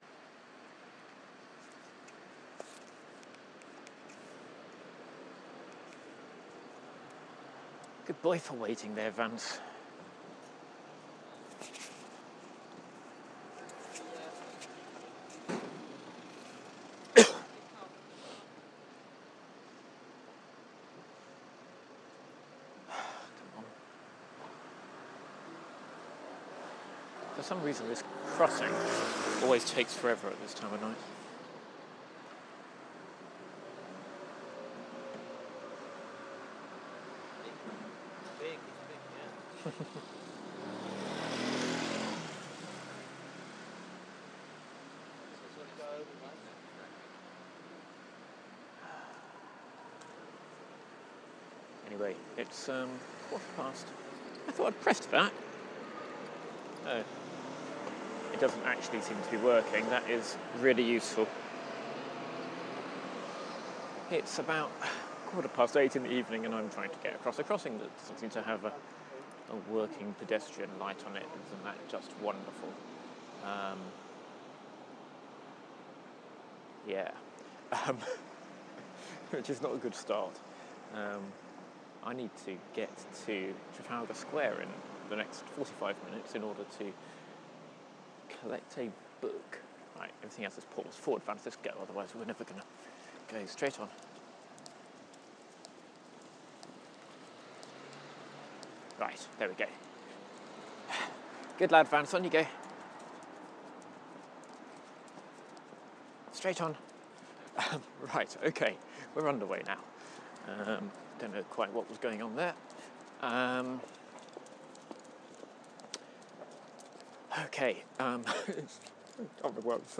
I walk with guide dog Vance from Marsham Street to Trafalgar Square in order to buy a book for a departing colleague. On the way I point out some of London's sights including Westminster Abbey, St James Park, the wrong end of Downing Street and Nelson's Column.